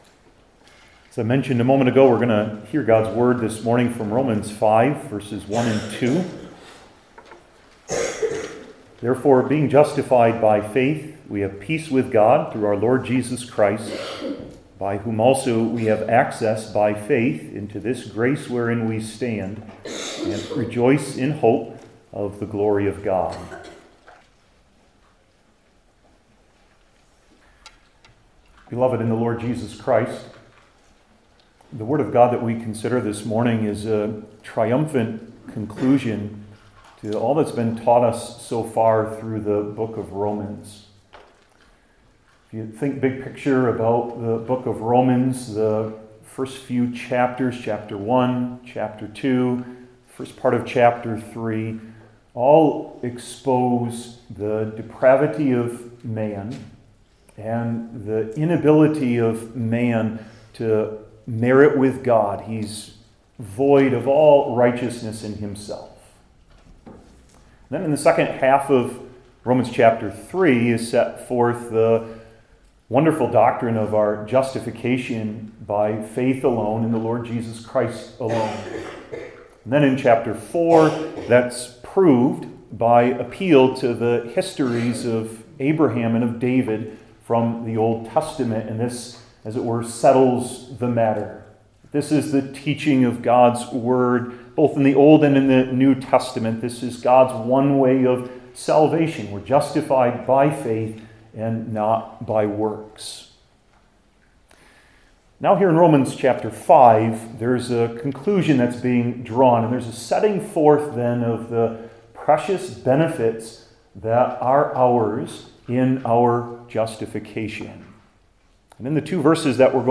New Testament Individual Sermons I. Peace II. Access III. Glory